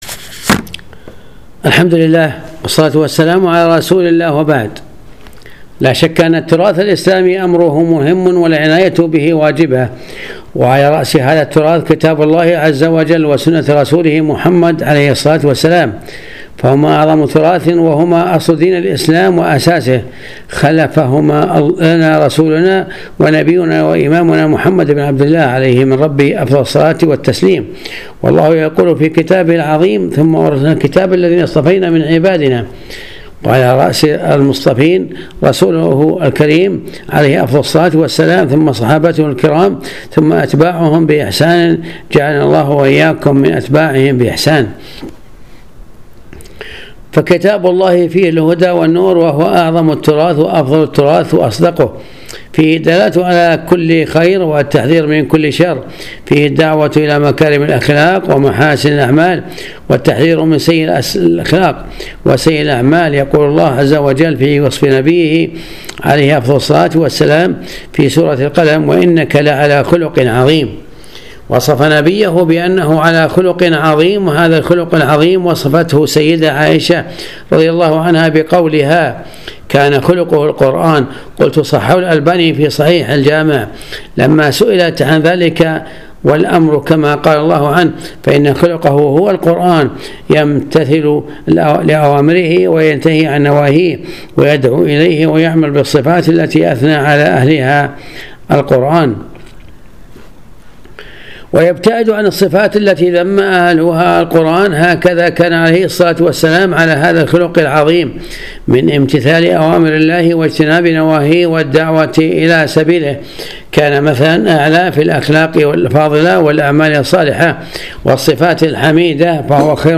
الدرس 230 ج 5 العناية بالتراث الإسلامي